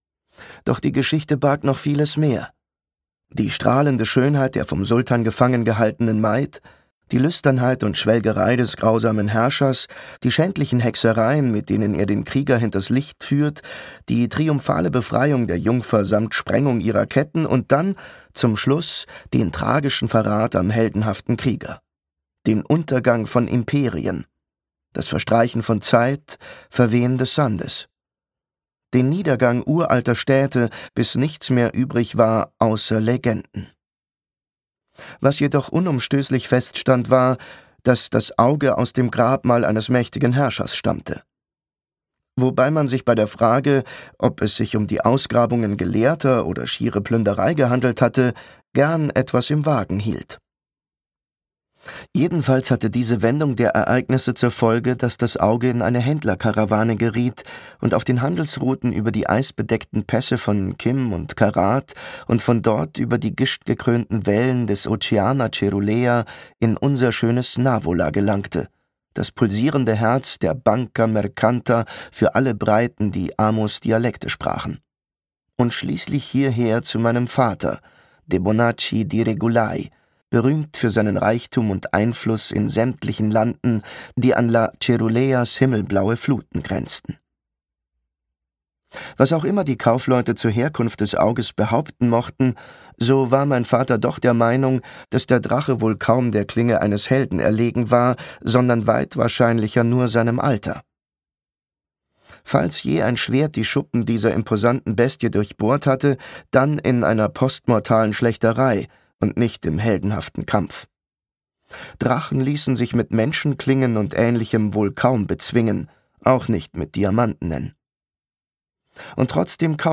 Navola - Paolo Bacigalupi | argon hörbuch
Gekürzt Autorisierte, d.h. von Autor:innen und / oder Verlagen freigegebene, bearbeitete Fassung.